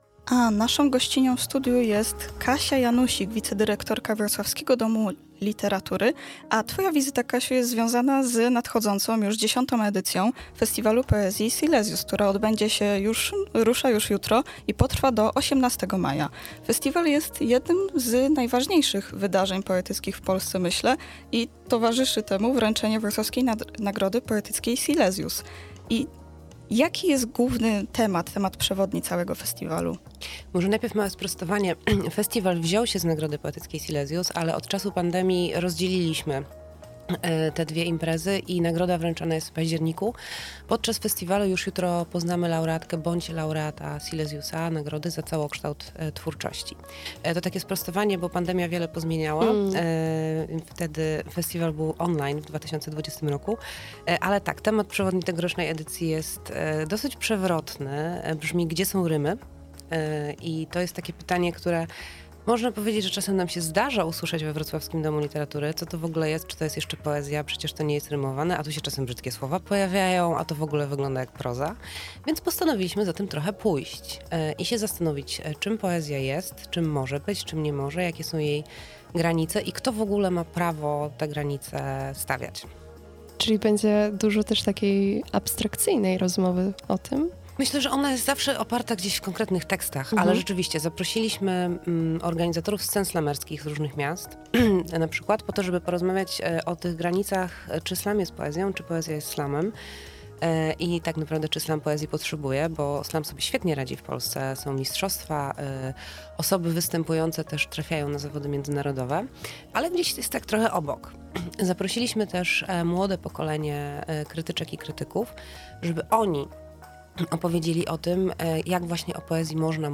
w audycji Pełna Kultura rozmawiały o festiwalu: